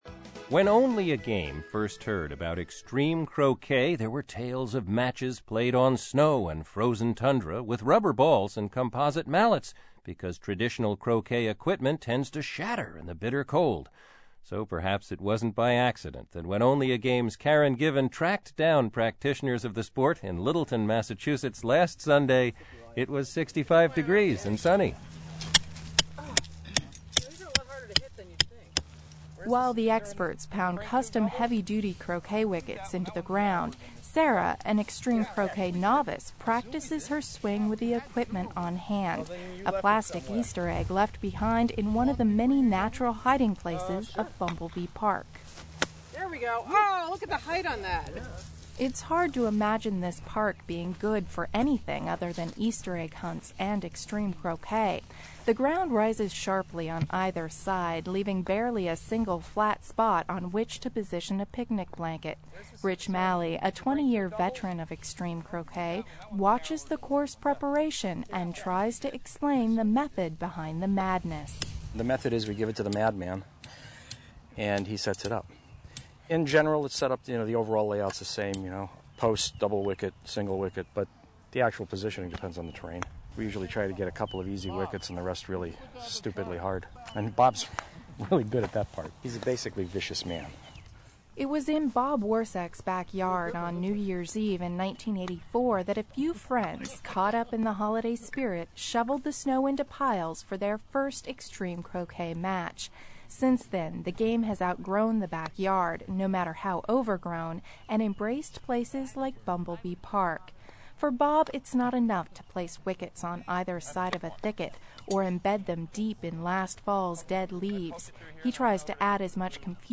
This is the day we were featured on the WBUR "It's Only A Game" broadcast.